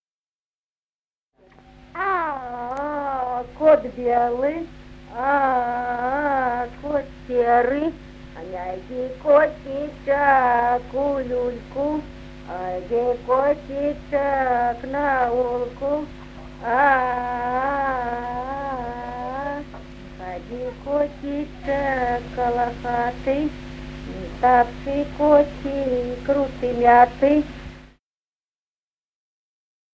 Народные песни Стародубского района «А-а, кот белый», колыбельная.
1959 г., с. Остроглядово.